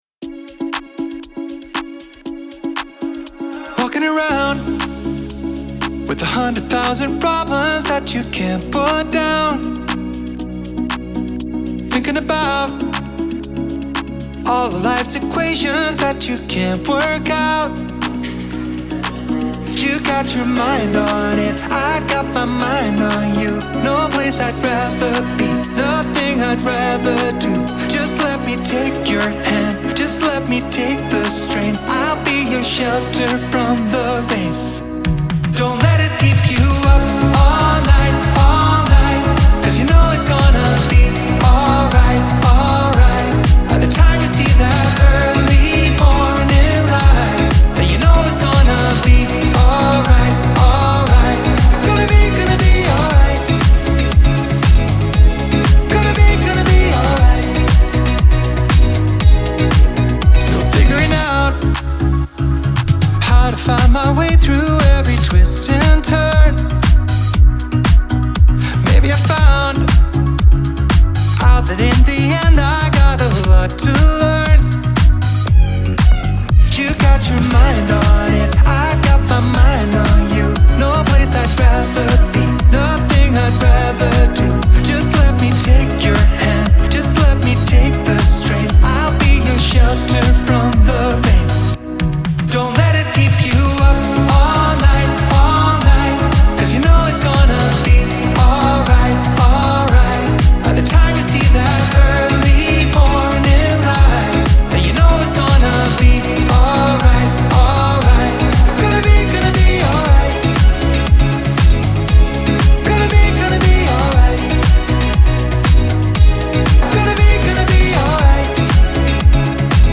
• Жанр: Pop, House, Dance